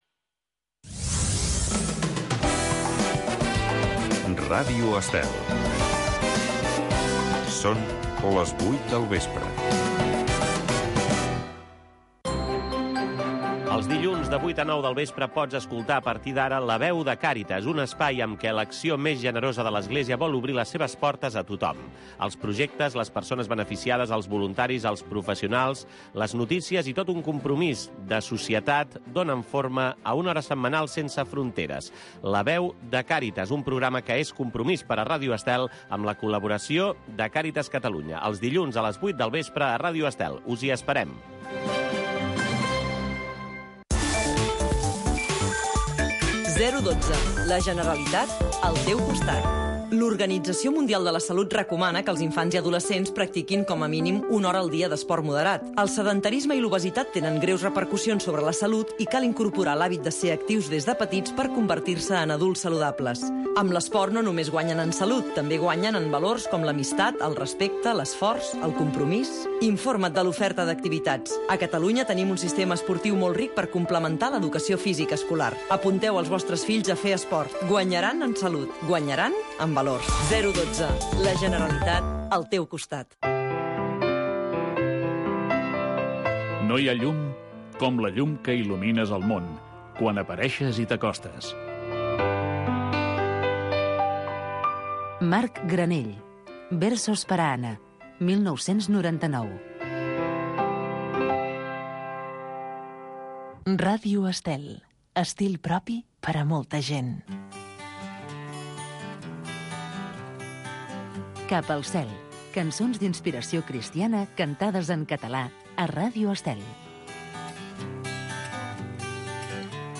Música religiosa